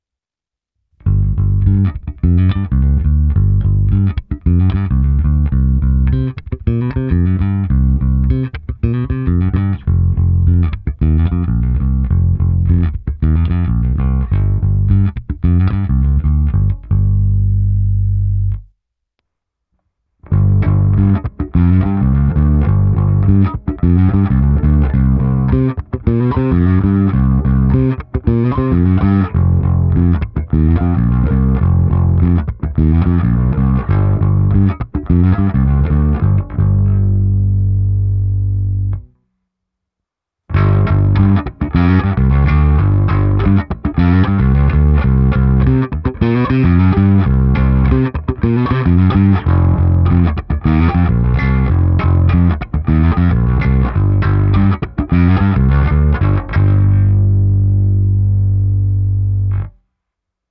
Zvuk je v rozsahu od čistého boostu po nakřáplý overdrive, ale to je nutno ovladač DRIVE vytočit hodně doprava, klidně až nadoraz.
Nahrál jsem i ukázku, kdy v první části je zvuk baskytary bez této krabičky, pak ukázka s mírným ovedrivem a nakonec pro porovnání ukázka s největším zkreslením, co krabička umí.
Pěkný zvuk, hezky to štěká smile.